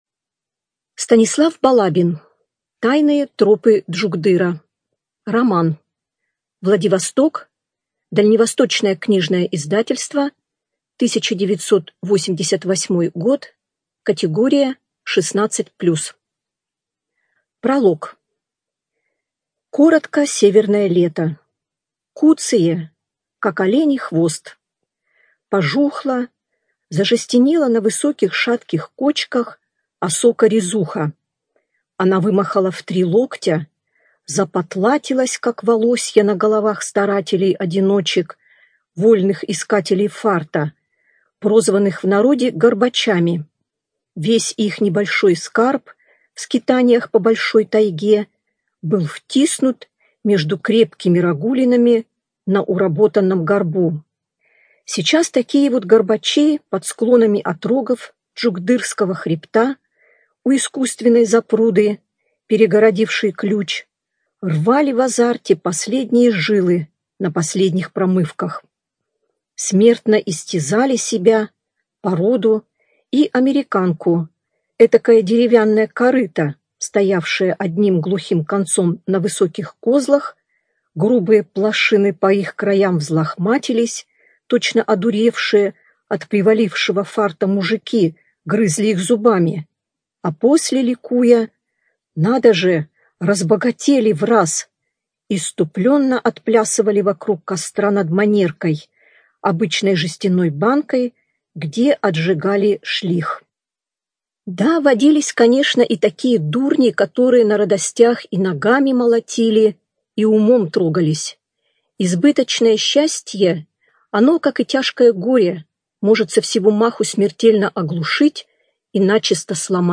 Студия звукозаписиПриморская краевая библиотека для слепых